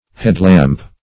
headlamp \head"lamp`\ (h[e^]d"l[a^]mp`), n.